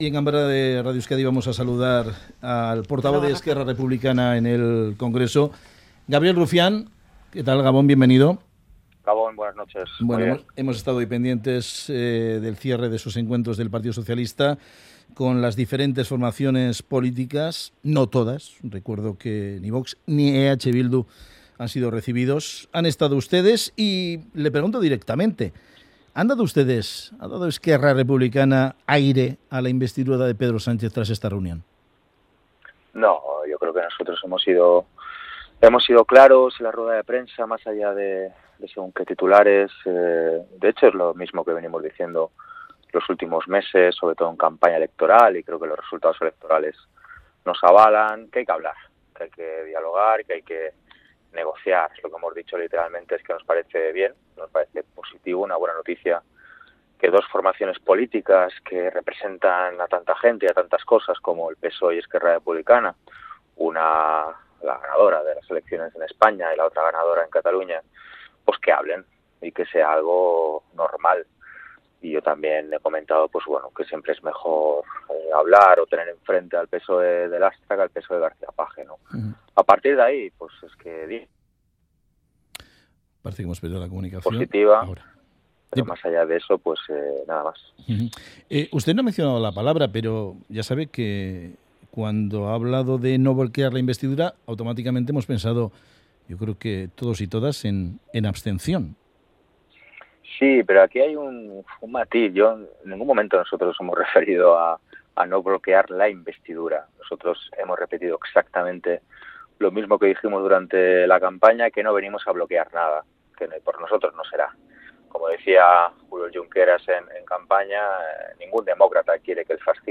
Audio: El portavoz de ERC, Gabriel Rufián, asegura que 'no hemos venido a bloquear nada, pero tampoco le daremos un cheque en blanco a Pedro Sánchez'.